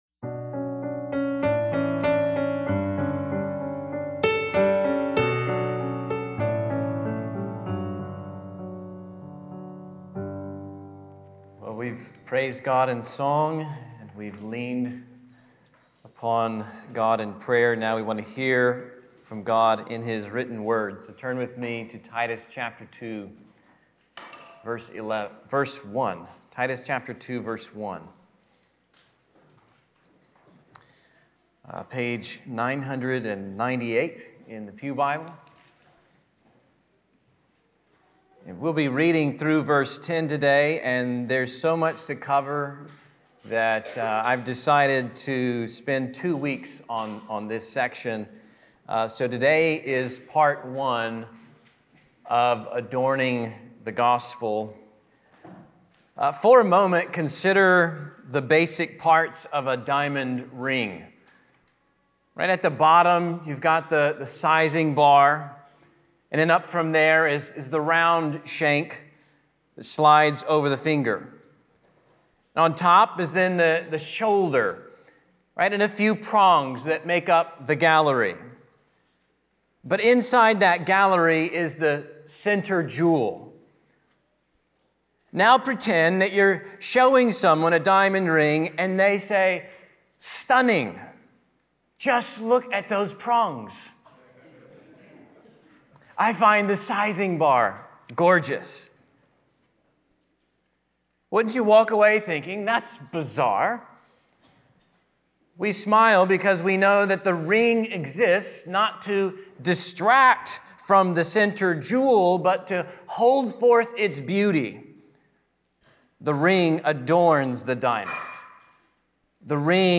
A local B-52's cover band full of great folks from your backyard. Last Thursday we brought the Fort Worth Roots Podcast to an acoustic set at The Usual and held a one-of-a-kind recording.